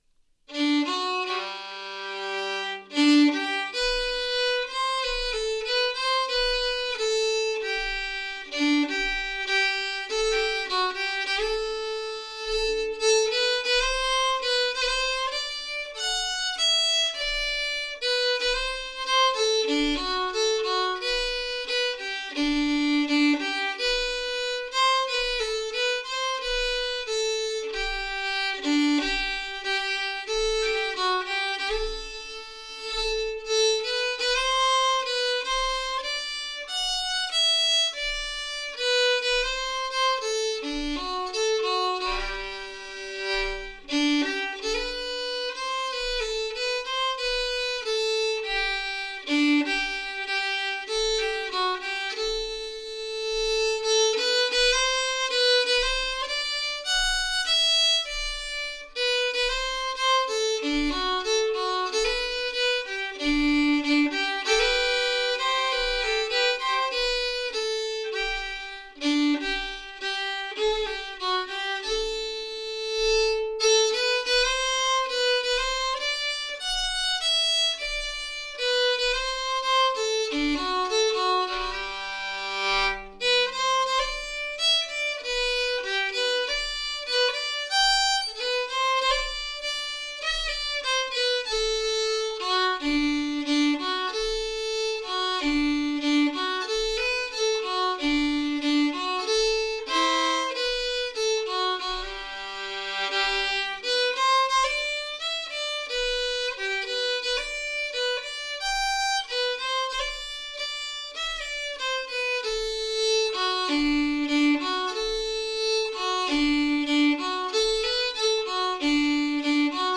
Tinbuder är en gånglåt skriven av Erik Momqvist från Söderås, Rättvik.
Melodi långsam
Tinbuder_slow.mp3